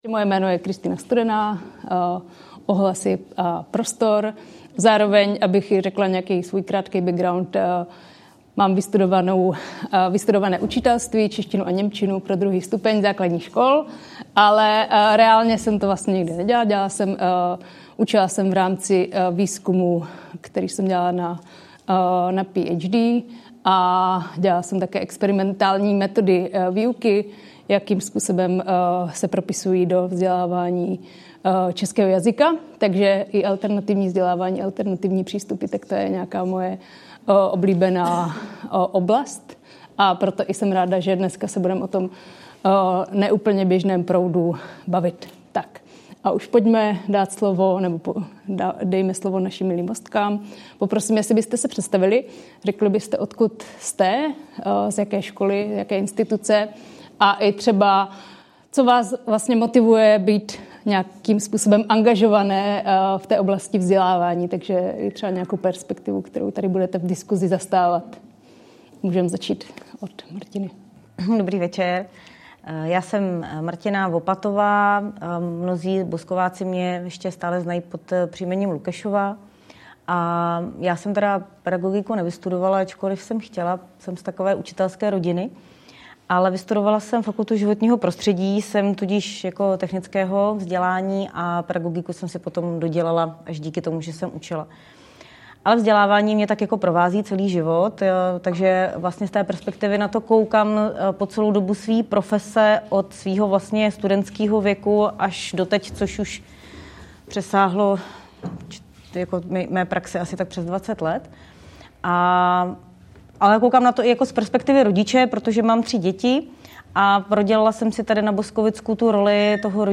Záznam živé debaty o vzdělávacích alternativách na Boskovicku. Co náš region nabízí a v jakých aspektech ještě zaostává?